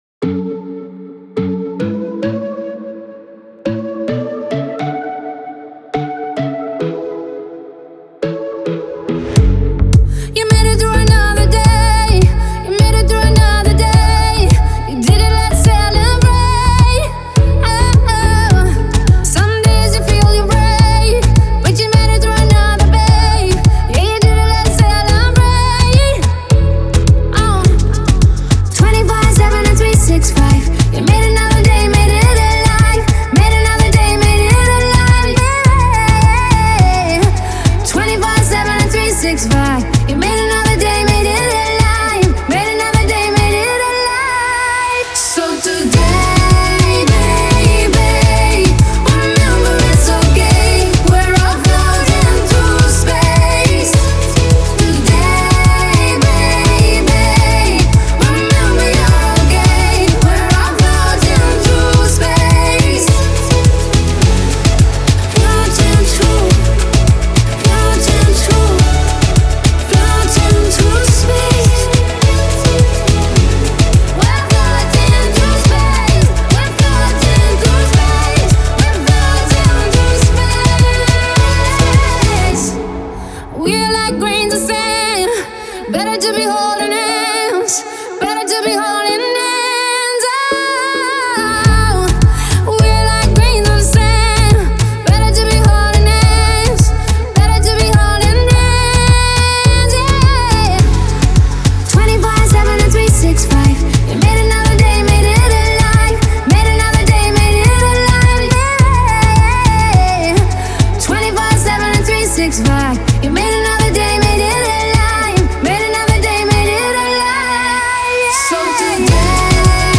это яркая и энергичная песня в жанре поп и EDM